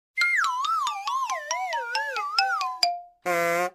Play, download and share vv_faint cartoon original sound button!!!!
vv-faint-cartoon.mp3